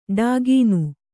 ♪ ḍāgīnu